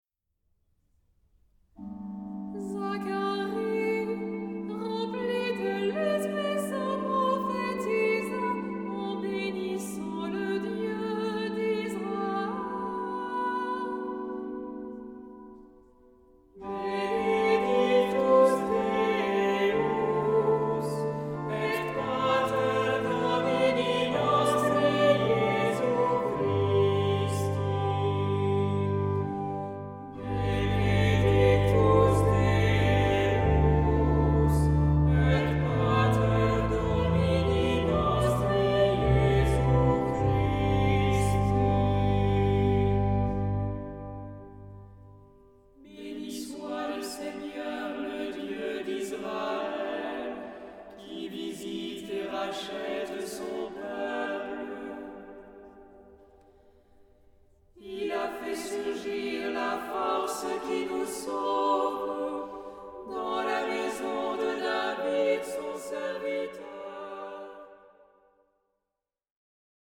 Género/Estilo/Forma: tropario ; Salmodia ; Sagrado
Carácter de la pieza : con recogimiento
Tipo de formación coral: SAH O SATB  (4 voces Coro mixto )
Instrumentos: Organo (1) ; Instrumento melódico (ad lib)
Tonalidad : sol mayor